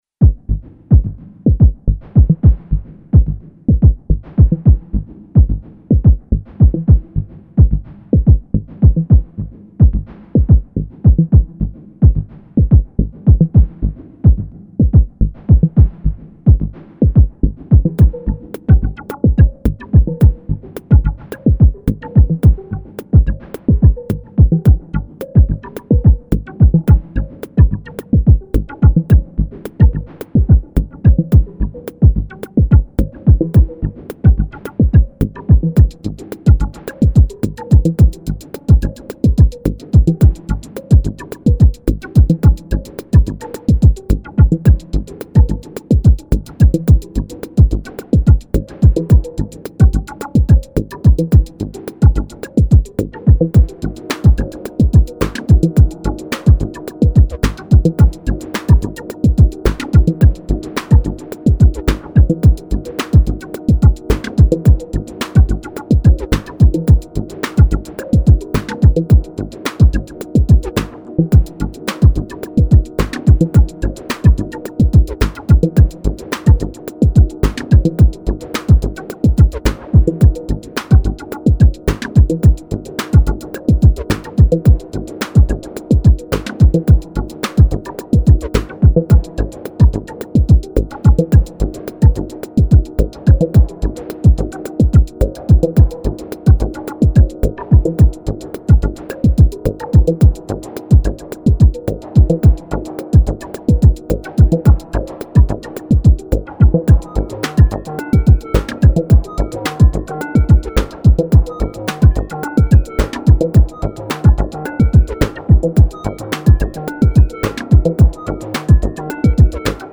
Elektron Gear Syntakt
Here’s some electro stuff: